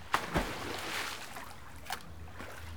SINGLE SP02R.wav